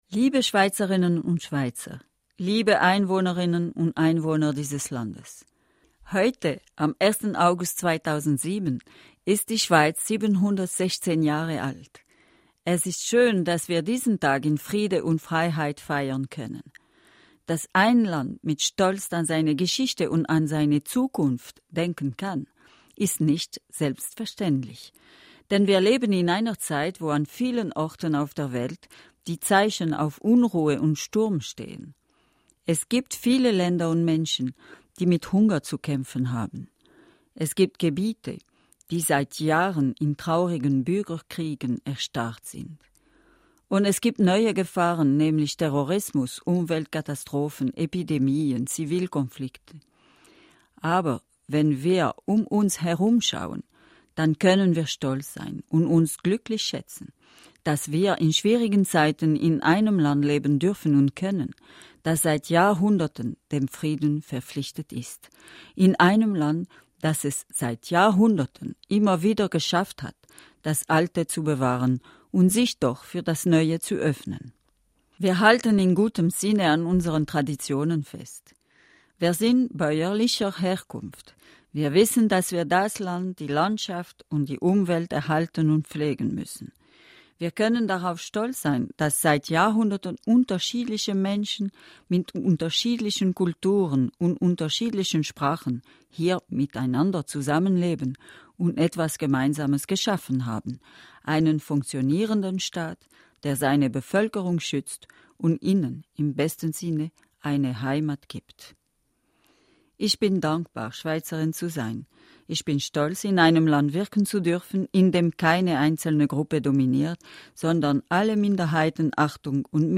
Die 1.August-Ansprache von Bundespräsidentin Calmy-Rey